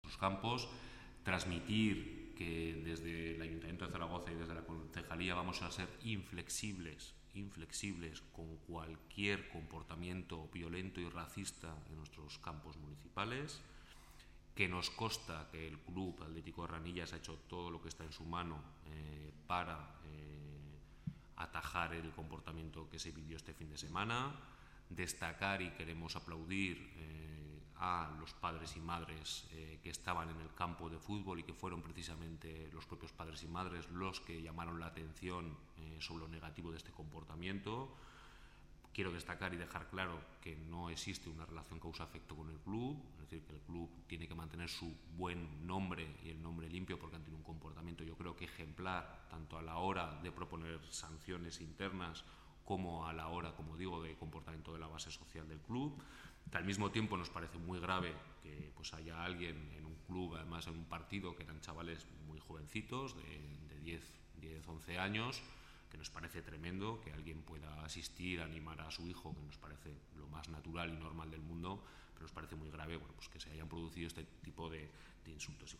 DECLARACIONES DEL CONCEJAL PABLO HÍJAR SOBRE EL EXPEDIENTE SANCIONADOR POR INSULTOS RACISTAS